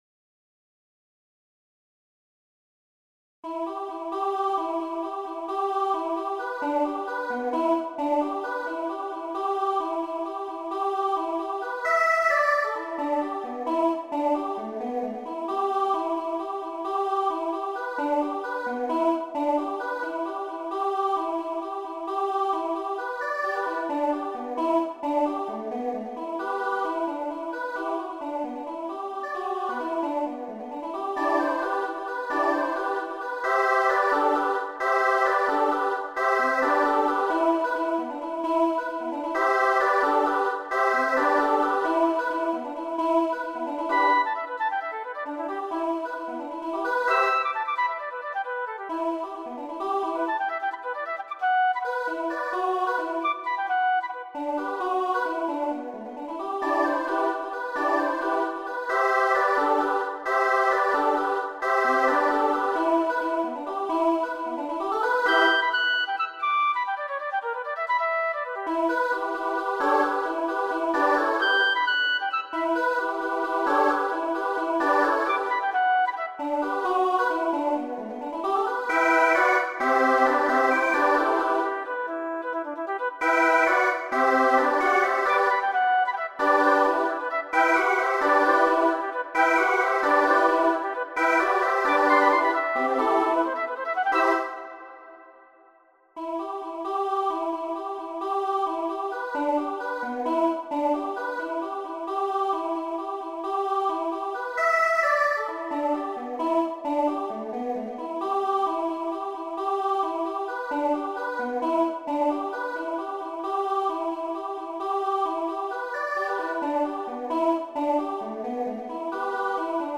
is for SSAA with flute and percussion
SSAA with flute and percussion